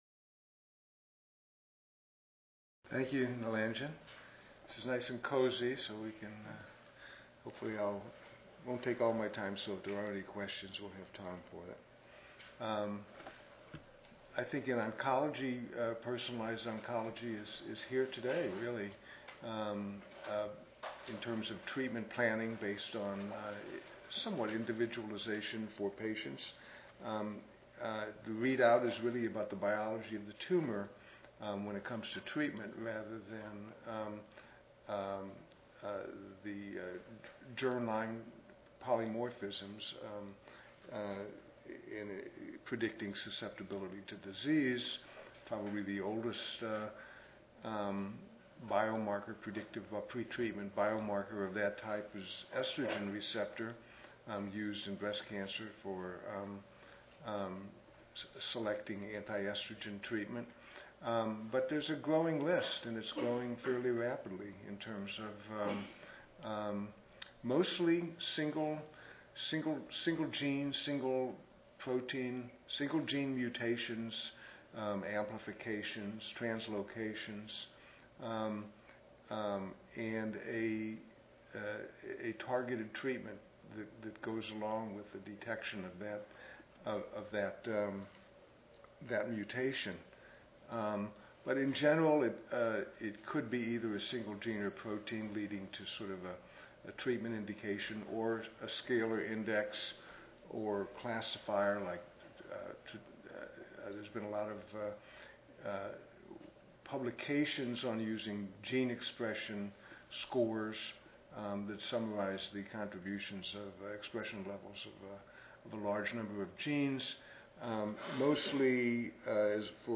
4417.0 Annual Special Session: Personalized medicine in genomics era: Current progresses and future trends Tuesday, November 1, 2011: 4:30 PM Oral Session Objectives: Discuss use of genomic technologies for design of clinical trials and public health applications.